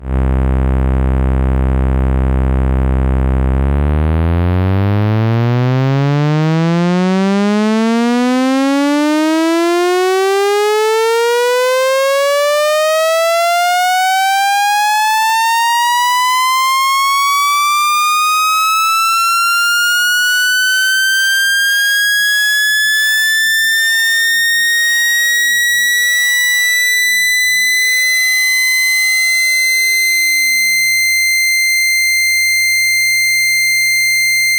In the sweeps, the lowest note is MIDI C2, which has a fundamental of 65Hz.
pcm-saw.wav